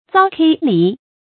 餔糟歠漓 bǔ zāo huài lí 成语解释 见“餔糟歠醨”。